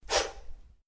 Melee Swing.wav